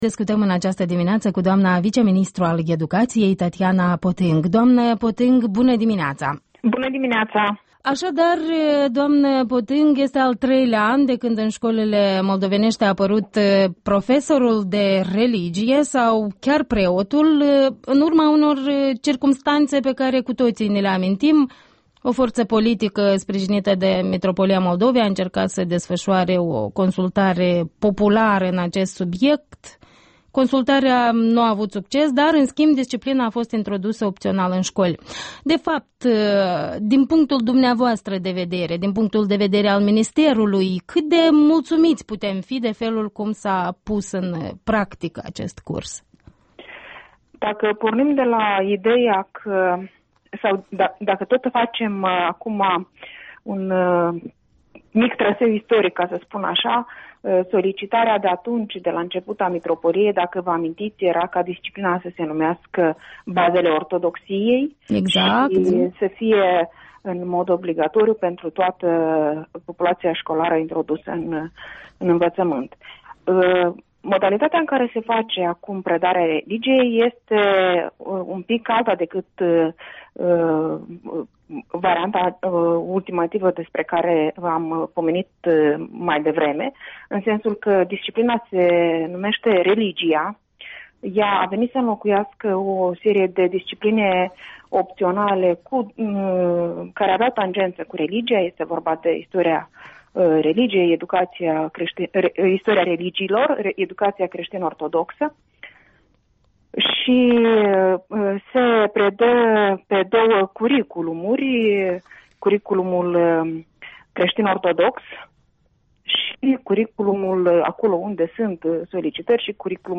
Interviul dimineții cu vice-ministrul Tatiana Potâng despre studiul religiei în școli